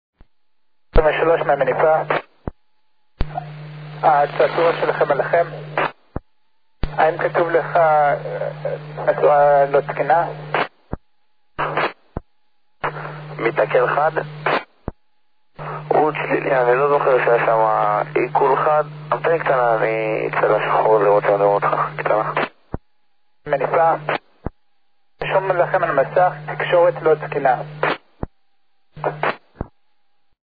Запись прохождения на Low band